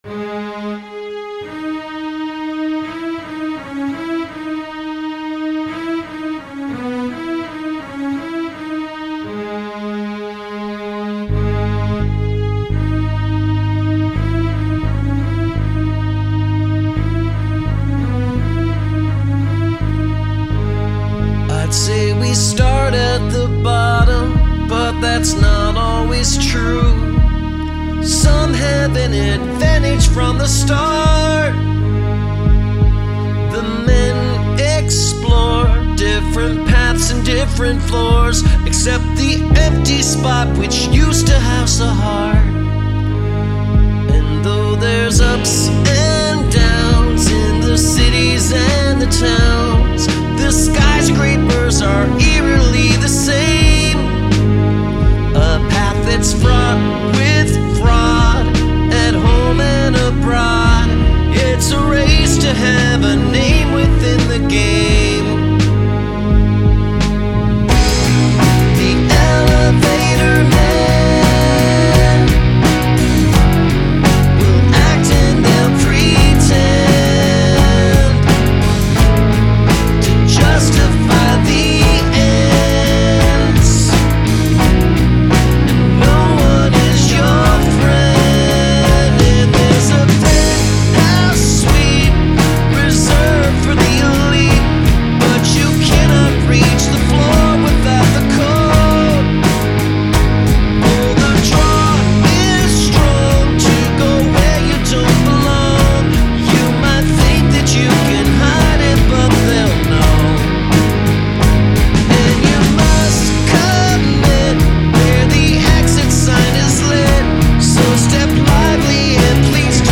Prominent musical repetition